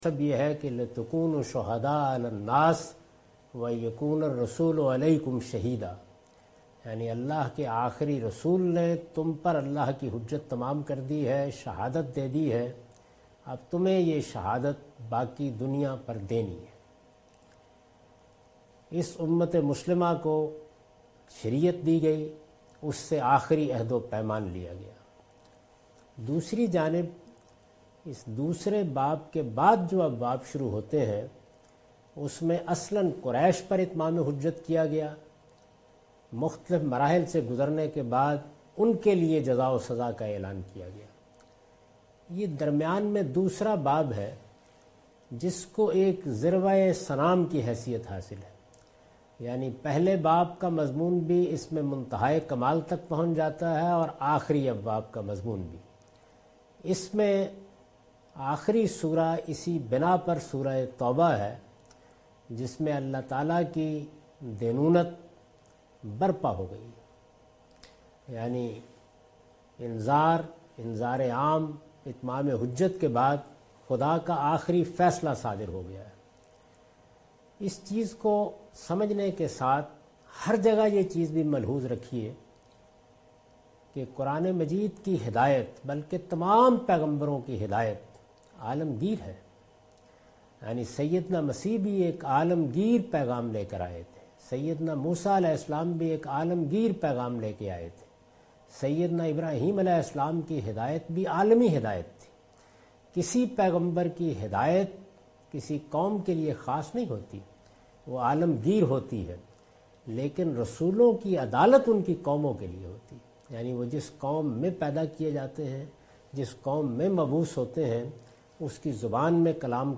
A Lecture of Tafseer ul Quran (Al-Bayan) by Javed Ahmed Ghamidi.